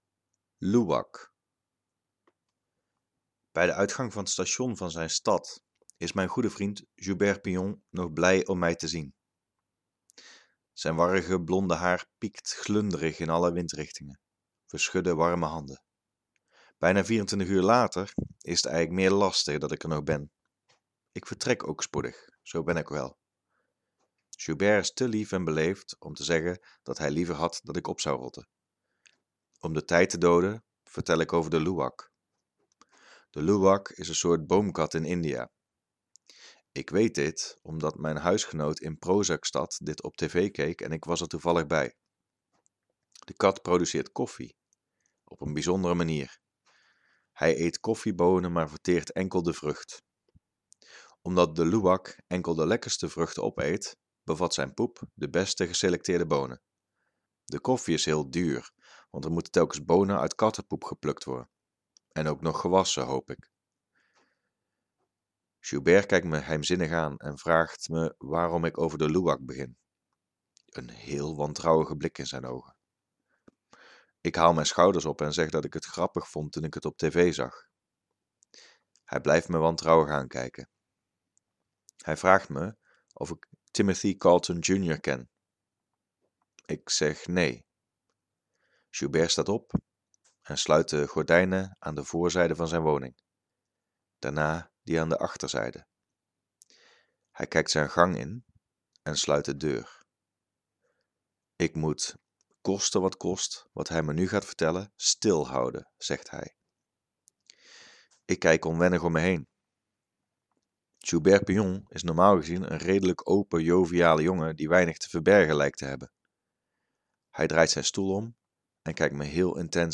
Audio stories Korte proza
Soundtrack: He knows my name / Ryan Adams